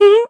scared-step.ogg.mp3